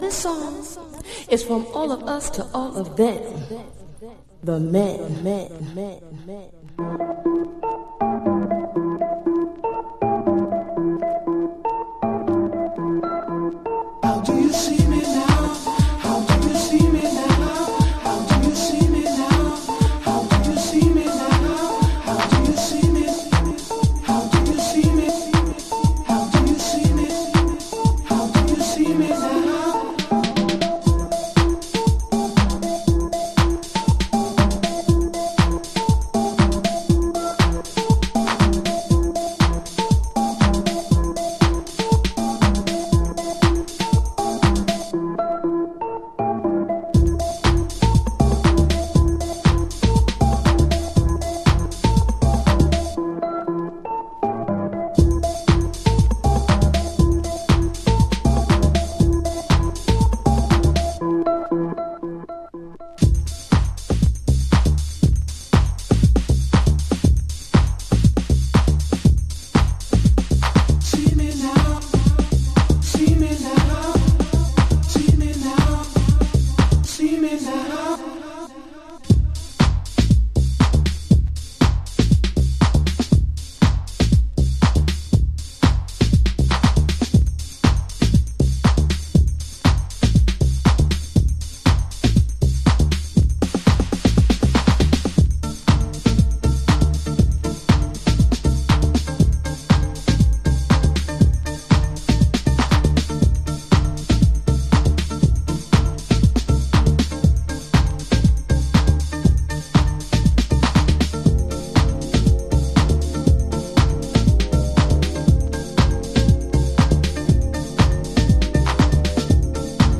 心に滲みるディープハウスオブソウル。